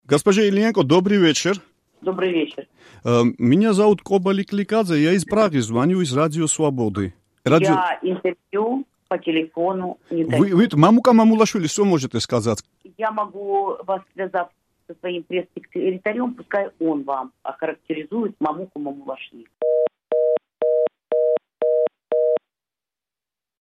სატელეფონო ინტერვიუ